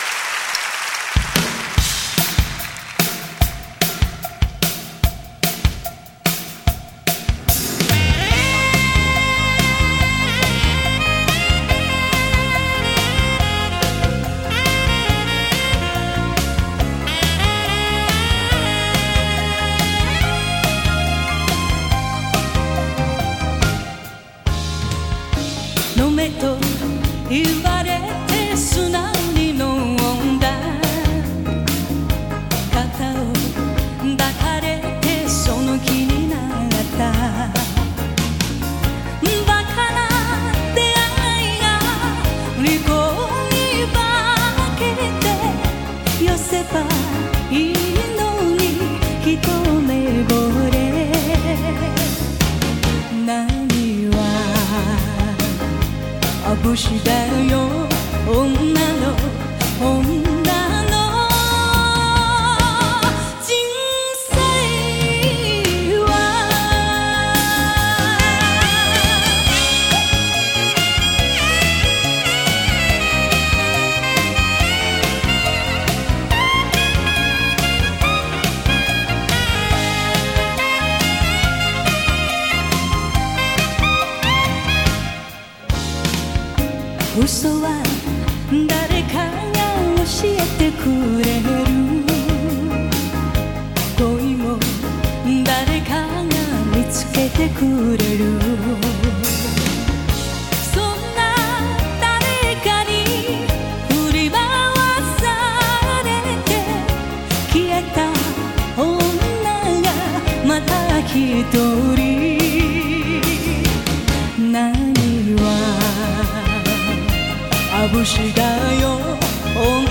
这场演唱会的录音水准，可以说是当年的最高标准，甚至超越了许多现在发行的现场录音质量。
而伴奏、和音、观众的现场声音也都独立录制。
乐队的配器也极其出色，充分展示了日本流行音乐的高超水平。